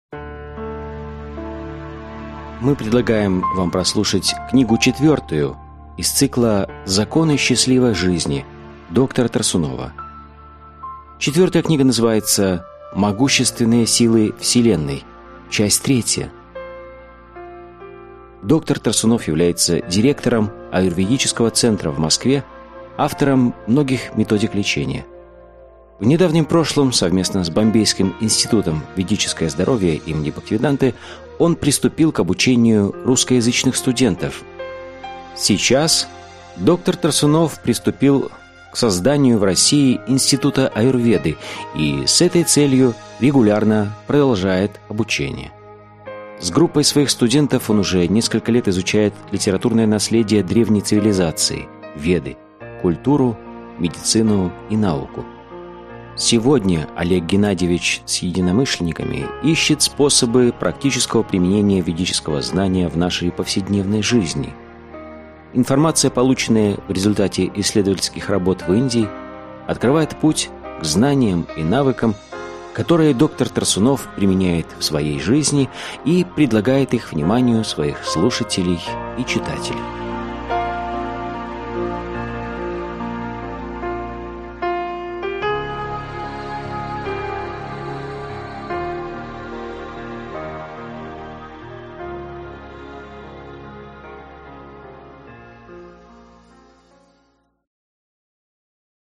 Аудиокнига Законы счастливой жизни. Том 4 | Библиотека аудиокниг